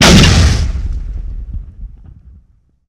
explosion_punchy_impact_03
Tags: Sci Fi Play